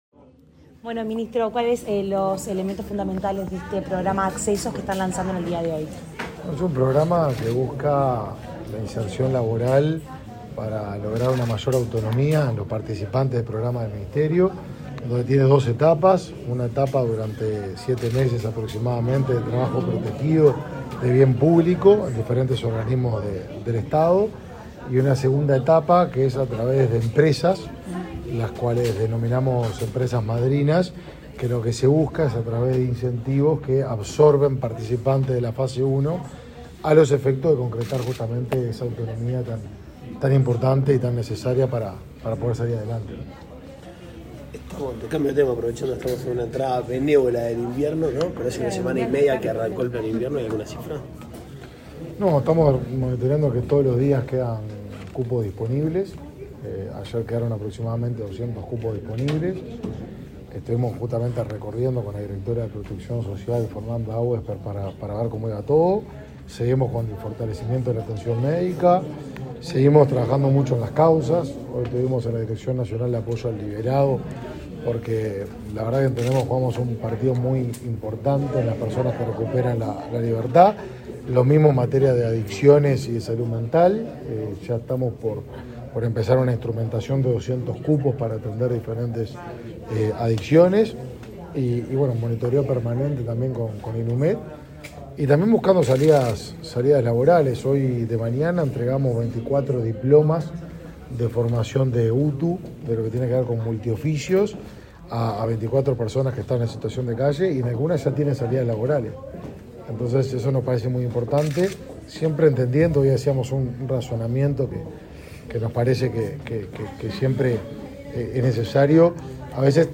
Declaraciones a la prensa de Desarrollo Social, Martín Lema
Declaraciones a la prensa de Desarrollo Social, Martín Lema 24/05/2022 Compartir Facebook X Copiar enlace WhatsApp LinkedIn Tras participar en la firma de convenio entre los ministerios de Trabajo y Seguridad Social y de Desarrollo Social, este 24 de mayo, Lema efectuó declaraciones a la prensa.